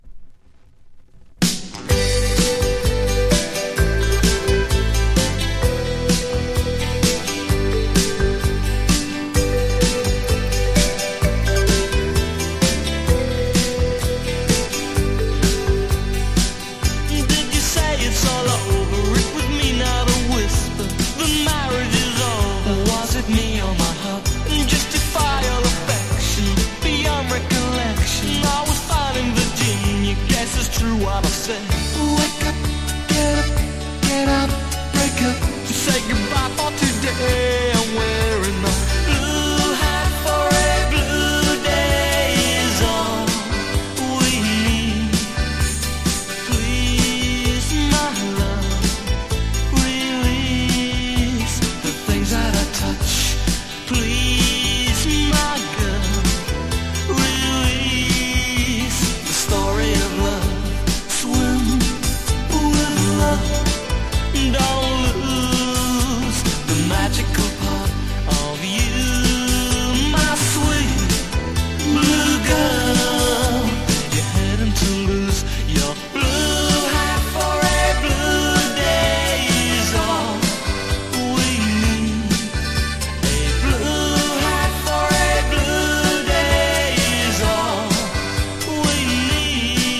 # NEO ACOUSTIC / GUITAR POP# NEW WAVE# 80’s ROCK / POPS
爽やかネオアコ！